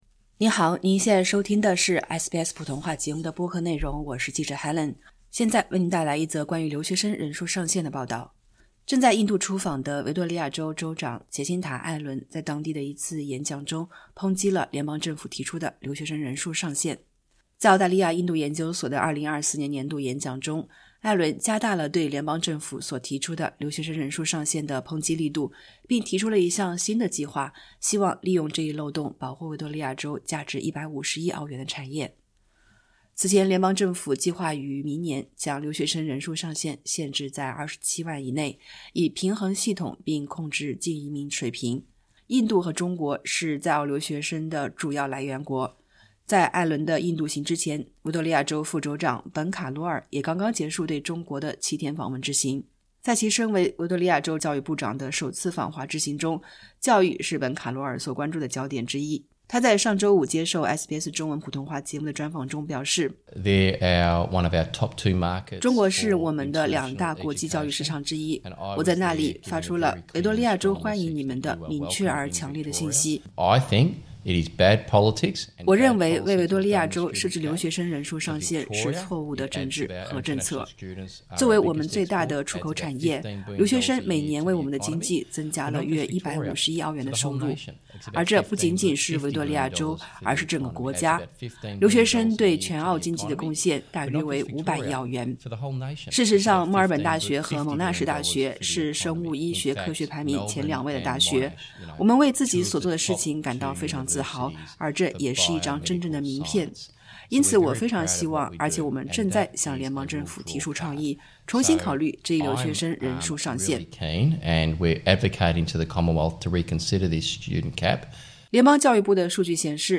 维多利亚州副州长本·卡罗尔在与SBS采访中提及，州政府已致函联邦政府，请求重新考虑留学生人数上限这一问题。